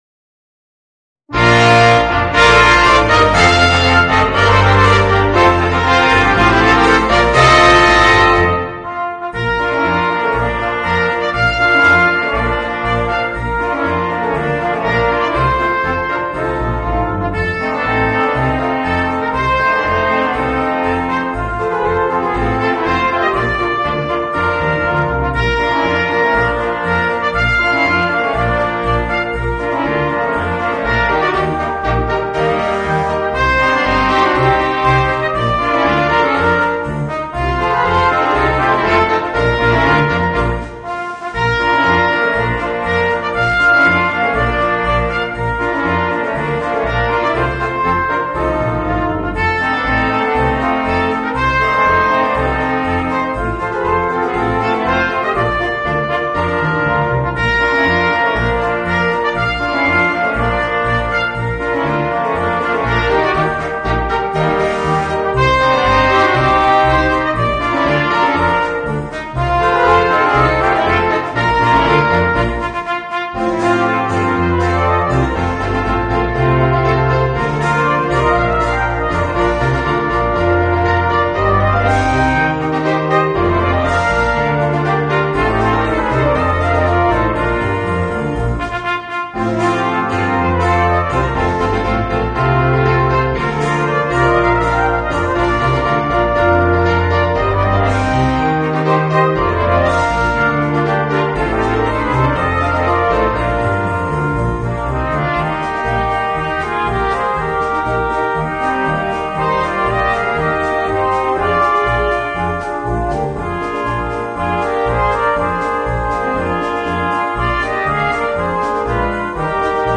Voicing: 5 - Part Ensemble and Rhythm Section